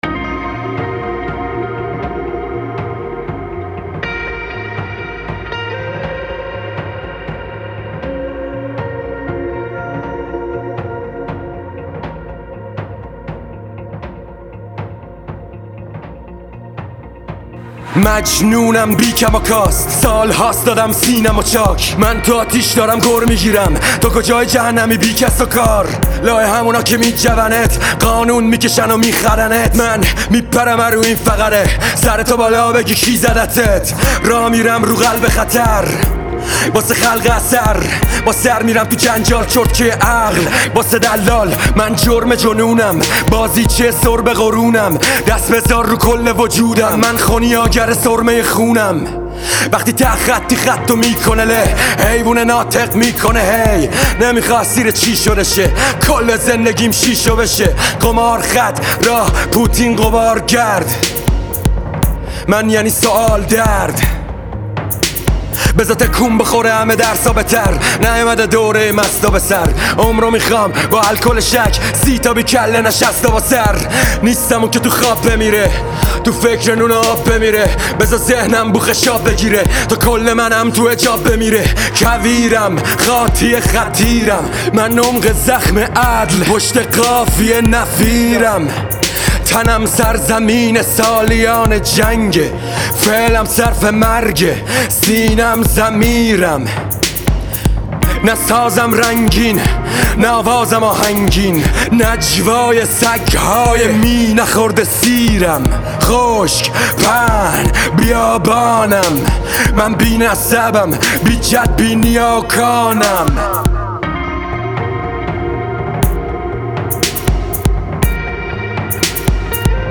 غمگین و احساسی